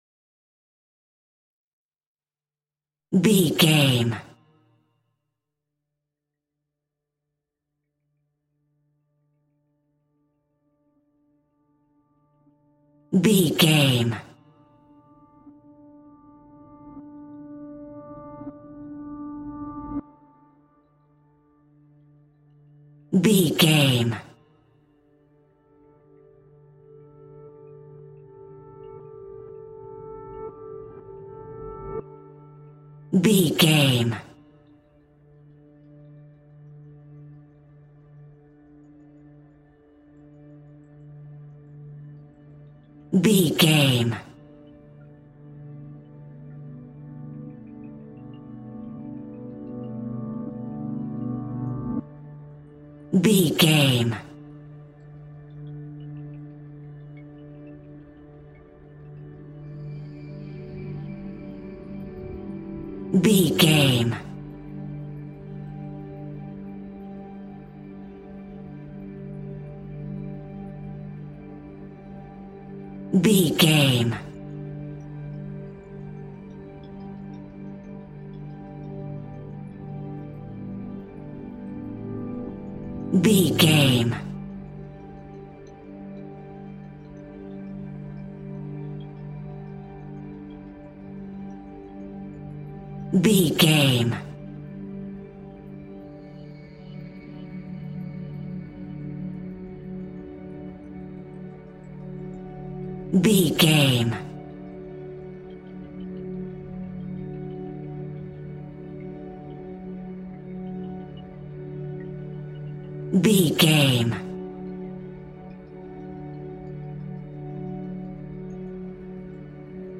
Ionian/Major
D♭
chilled
laid back
Lounge
sparse
new age
chilled electronica
ambient
atmospheric